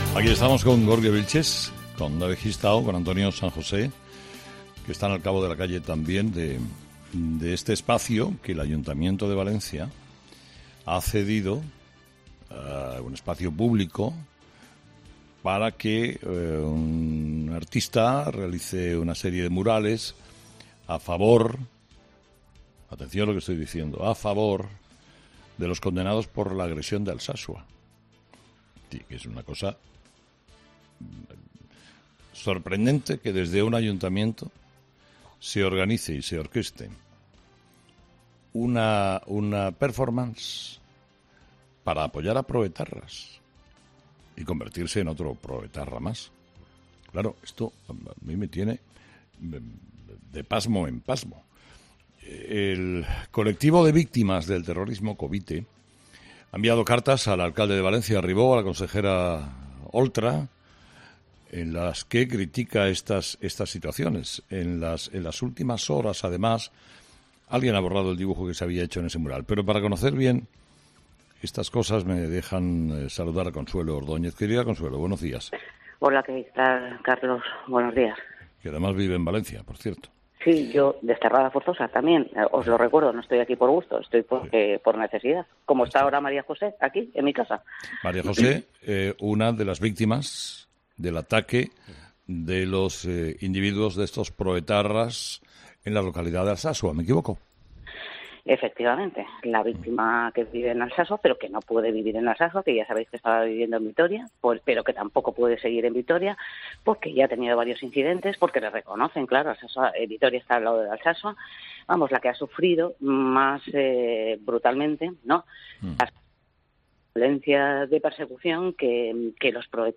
Entevista a Consuelo Ordóñez sobre el espacio cedido en Valencia para mostrar apoyo a los agresores de Alsasua